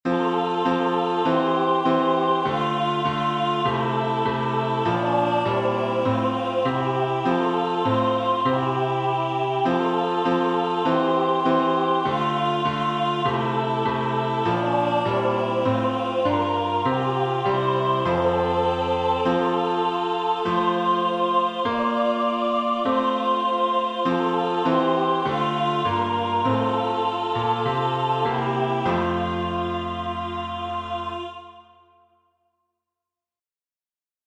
Look, Ye Saints! The Sight Is Glorious Words by Thomas Kelly, 1809 Tune: CORONAE by William H. Monk, 1871 Key signature: F major (1 flat) Time signature: 4/4 Public Domain 1.
Look_Ye_Saints_The_Sight_Is_Glorious_FMajor.mp3